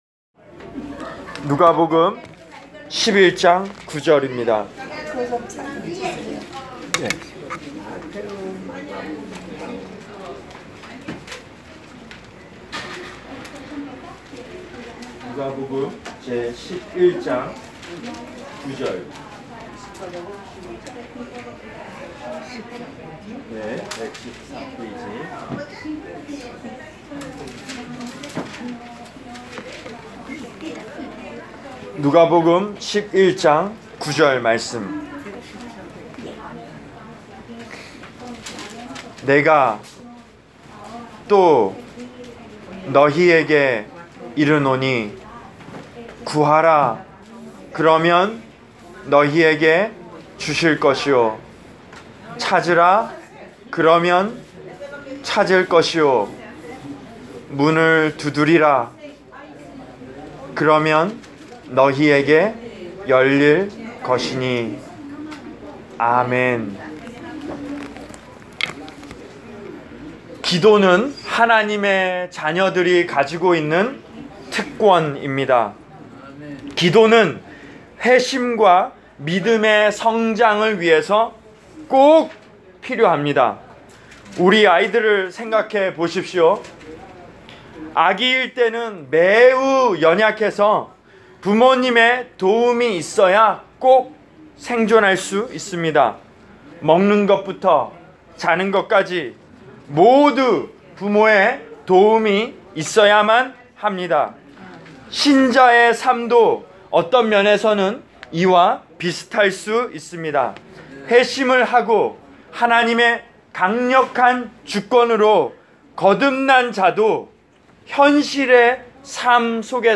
Preached for: Hudson View Rehab Center at North Bergen, N.J.